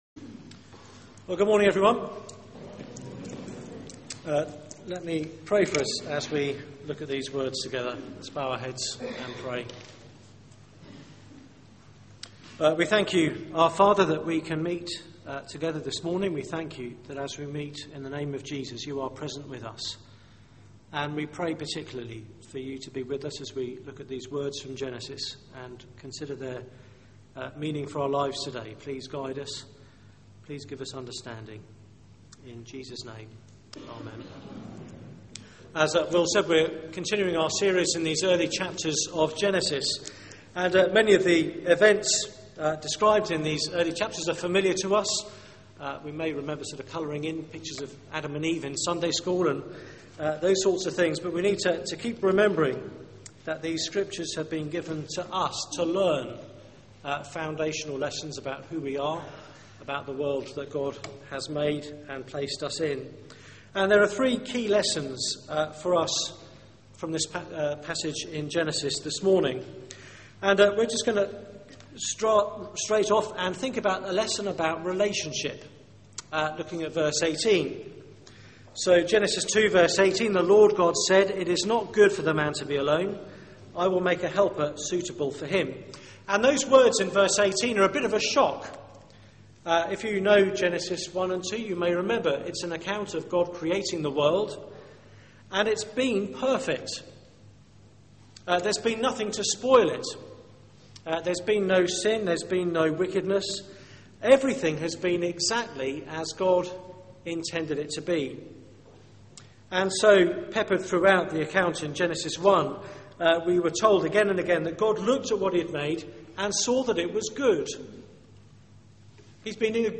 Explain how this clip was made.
Media for 9:15am Service on Sun 02nd Dec 2012 09:15 Speaker